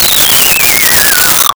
Comical Descent
Comical Descent.wav